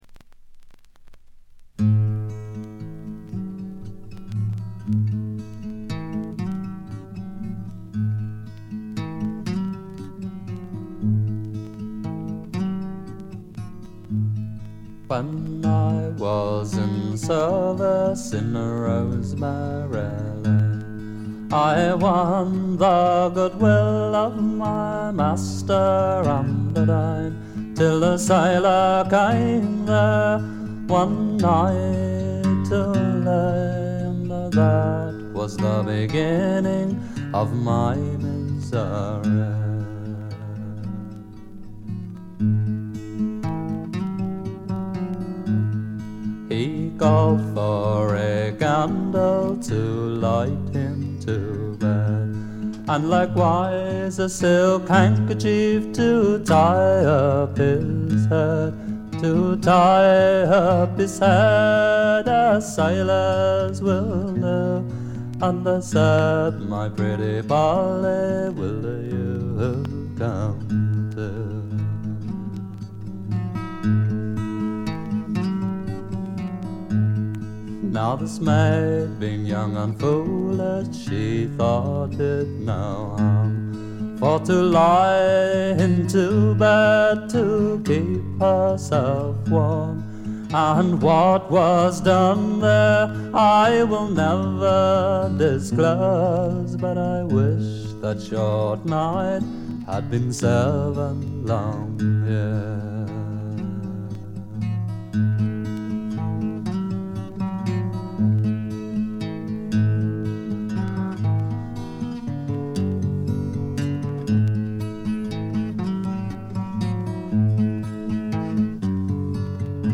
軽微なバックグラウンドノイズ、チリプチ少し。
ゲストミュージシャンは一切使わずに、自作とトラッドを味のあるヴォーカルと素晴らしいギターで表情豊かに聴かせてくれます。
試聴曲は現品からの取り込み音源です。